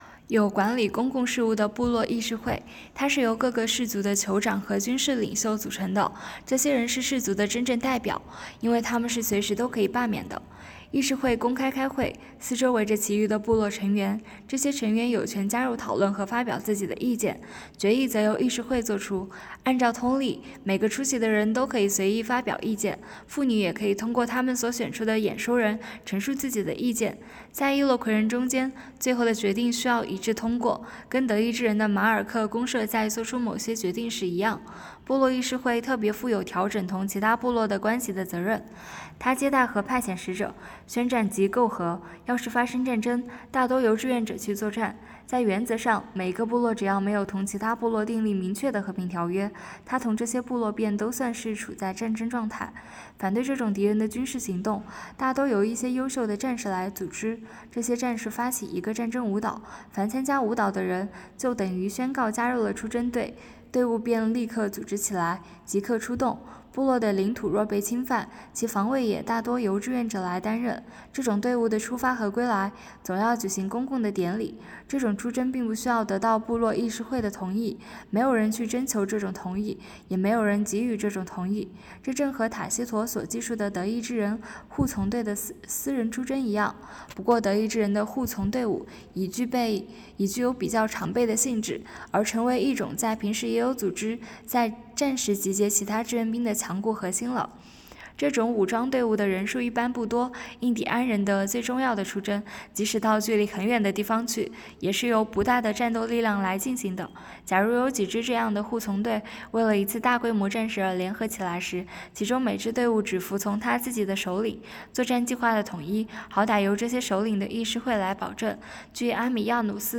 “读经典、悟原理”——2025年西华大学马克思主义经典著作研读会接力诵读（03期）